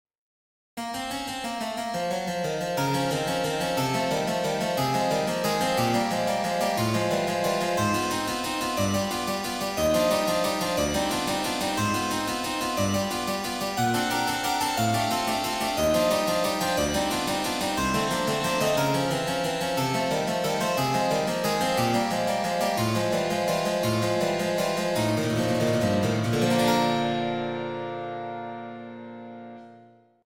Op.13 - Piano Music, Solo Keyboard - Young Composers Music Forum